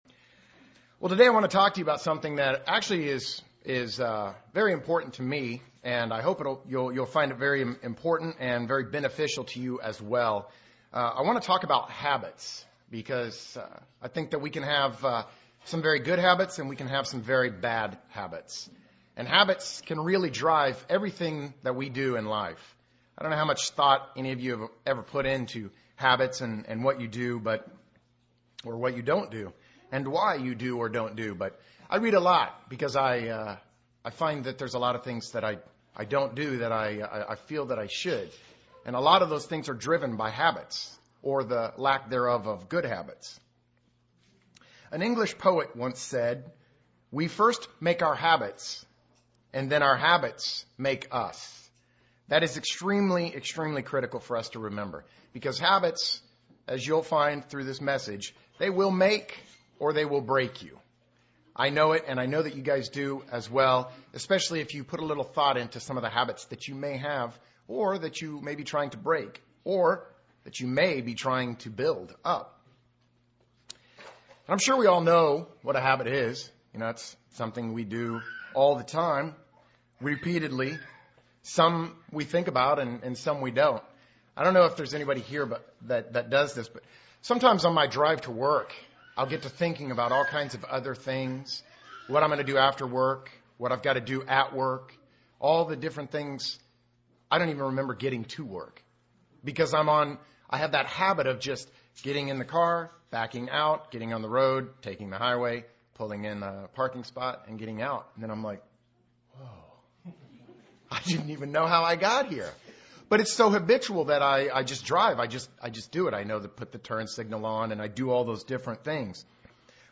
This sermon discusses successful habits to improving your walk with God.